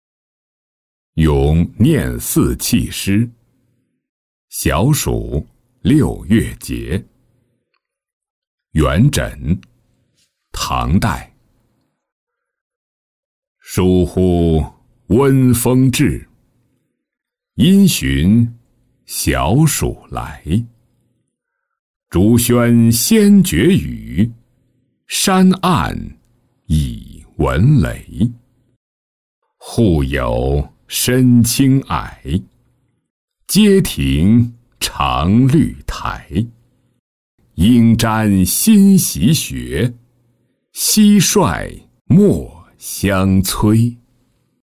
咏廿四气诗·小暑六月节-音频朗读